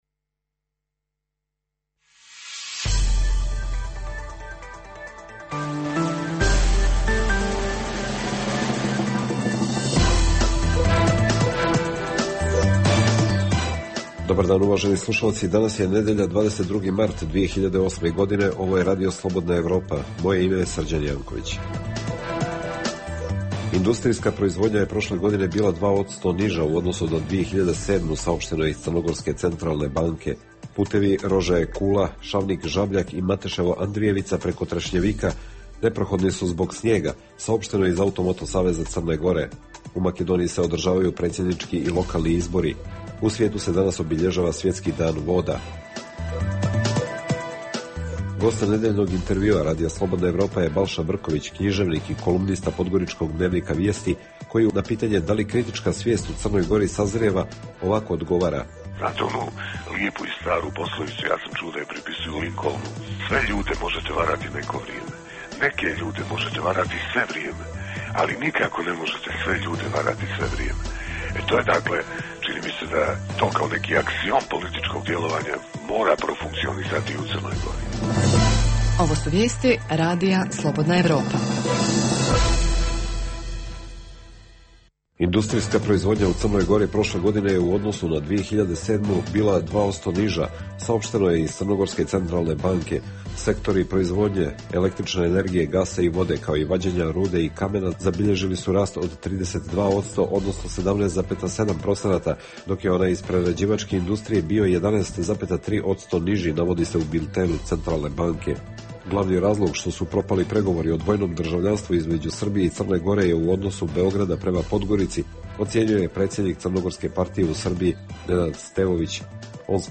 Emisija namijenjena slušaocima u Crnoj Gori. Sadrži lokalne, regionalne i vijesti iz svijeta, rezime sedmice, intervju "Crna Gora i region", tematske priloge o aktuelnim dešavanjima u Crnoj Gori i temu iz regiona.